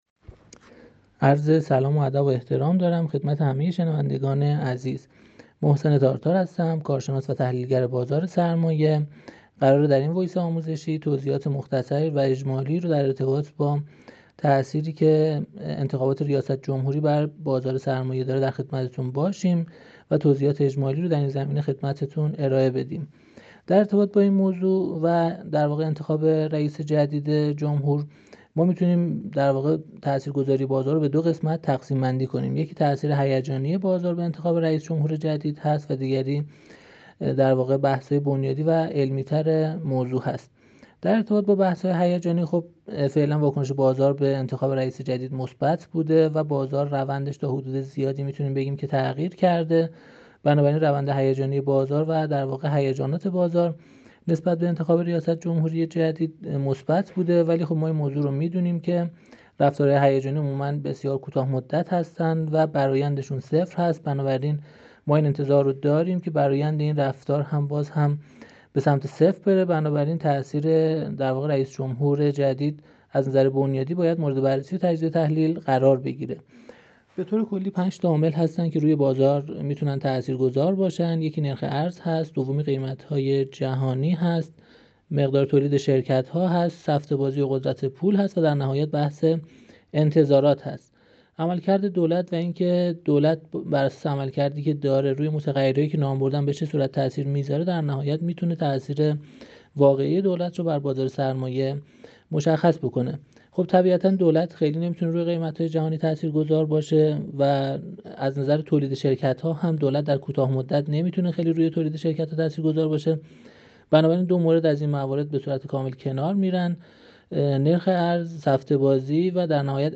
کارشناس و تحلیلگر بازار سرمایه